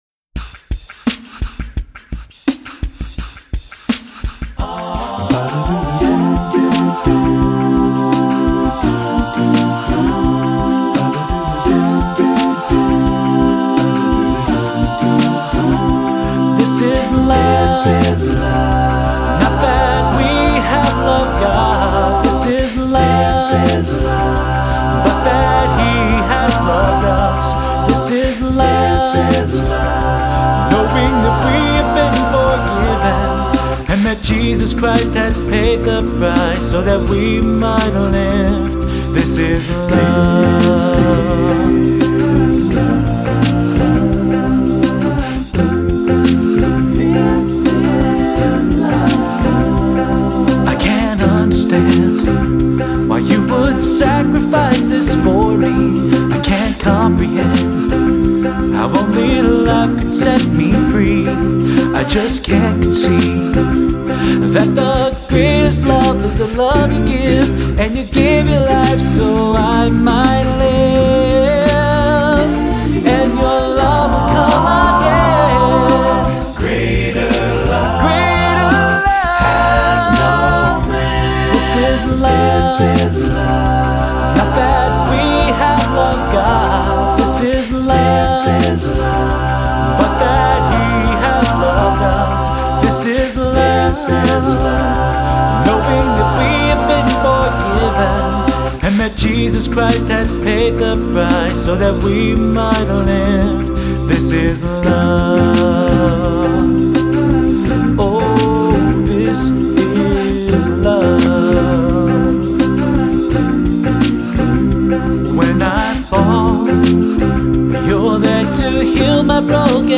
all-vocal, contemporary Gospel group